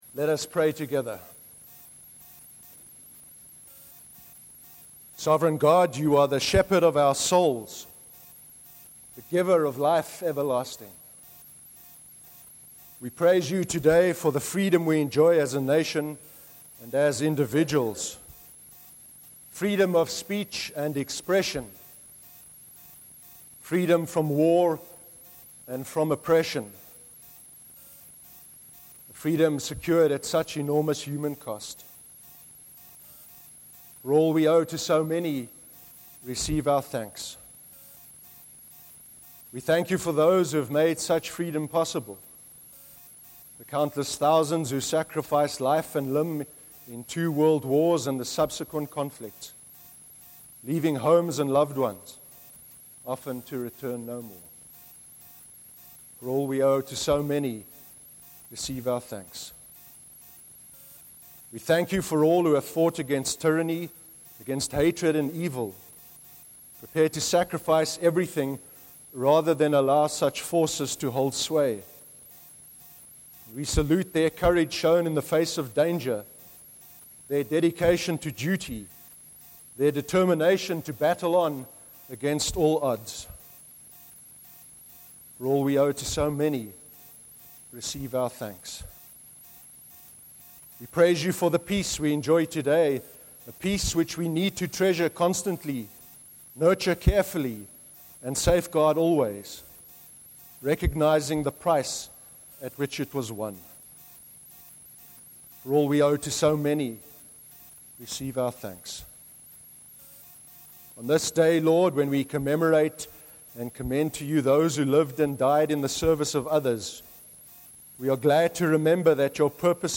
In our Remembrance Sunday Service, we remembered with great sadness the people who have given up their lives in the wars that have been fought.
A recording of the service’s sermon is available to play below, or by right clicking on this link to download the sermon to your computer.